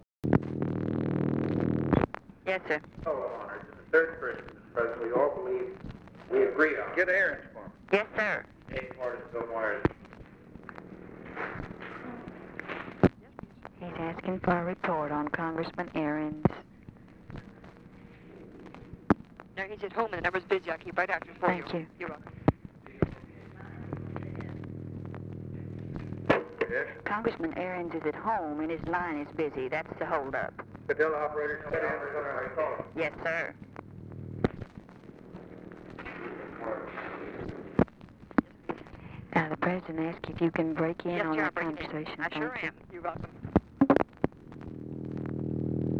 Conversation with OFFICE SECRETARY, November 29, 1963
Secret White House Tapes